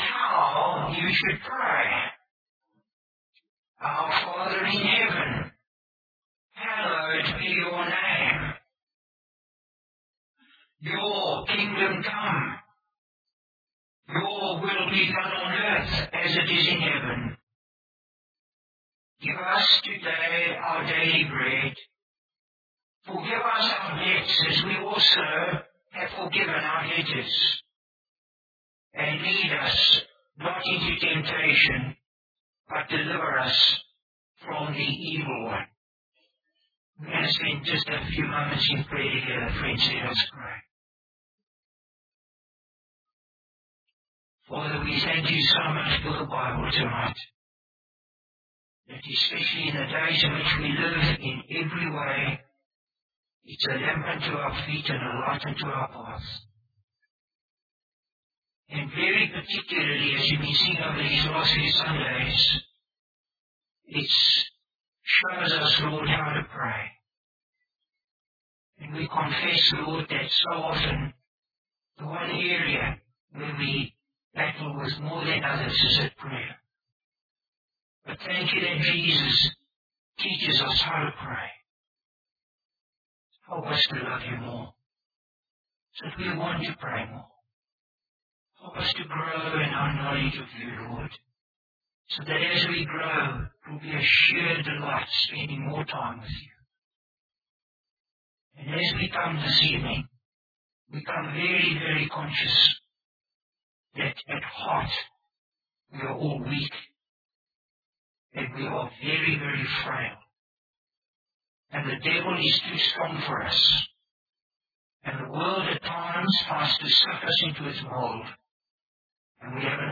Bible Text: Luke 11: 14 | Preacher: Bishop Warwick Cole-Edwards | Series: Lord Teach us to Pray